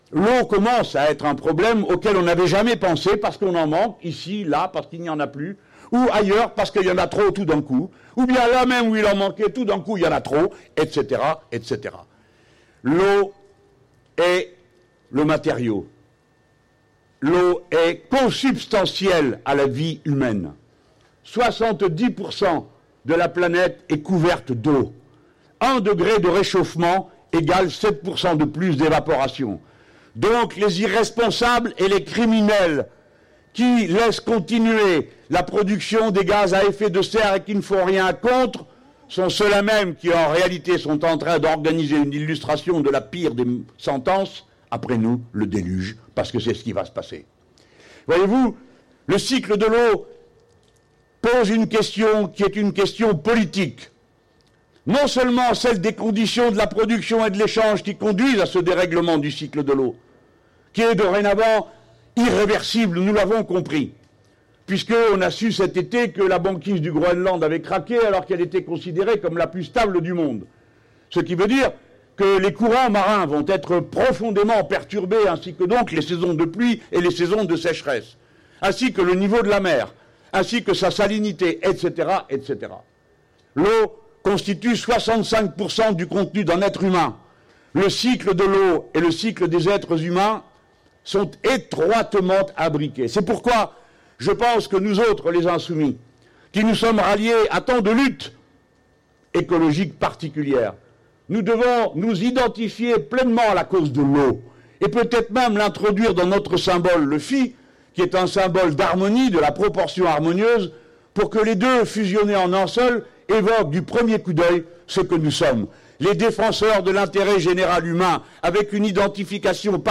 Nous allons inciter les Français à lui mettre une raclée démocratique», lance Jean-Luc Mélenchon devant quelque 2 000 personnes réunies à Marseille dans le cadre de l’université d’été de la France Insoumise.